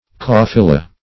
kafilah - definition of kafilah - synonyms, pronunciation, spelling from Free Dictionary Search Result for " kafilah" : The Collaborative International Dictionary of English v.0.48: Kafilah \Ka"fi*lah\, n. See Cafila .